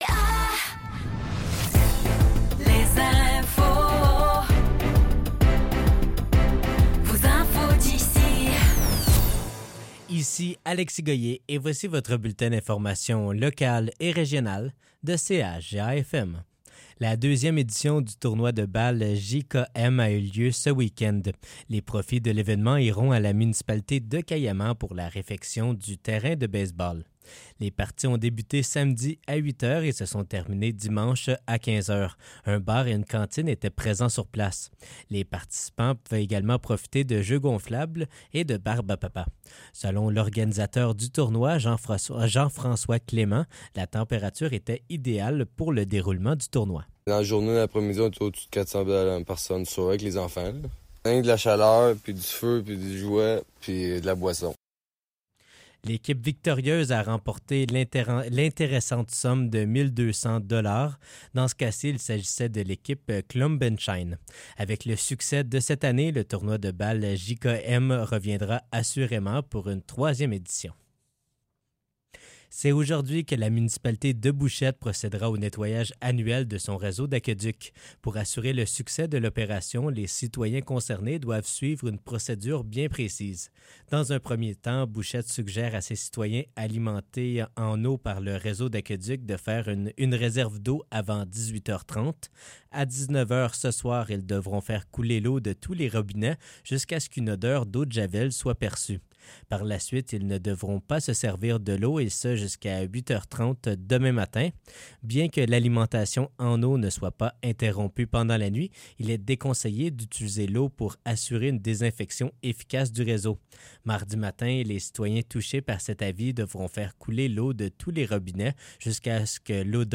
Nouvelles locales - 17 juin 2024 - 15 h